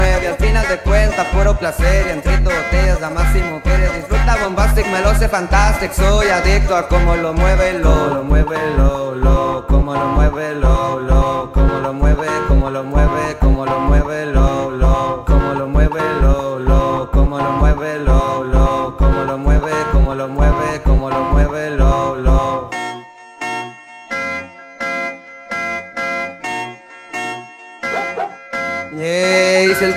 Жанр: Латино
Urbano latino